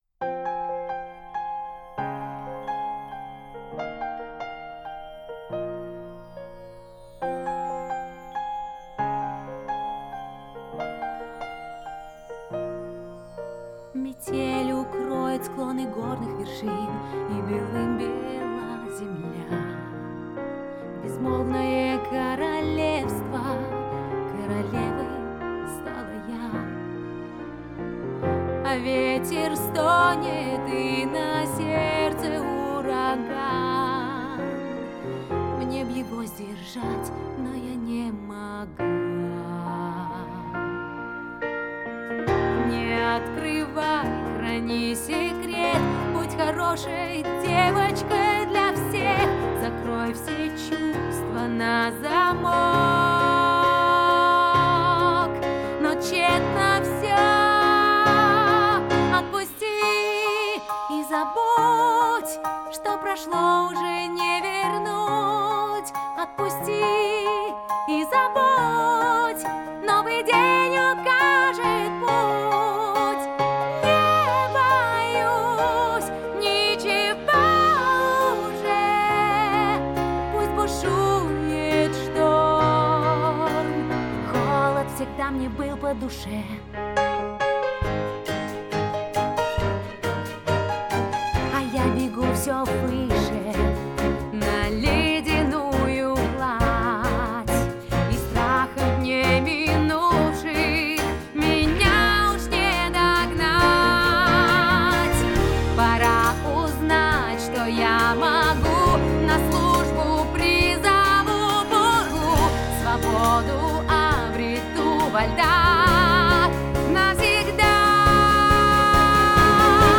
• Категория: Детские песни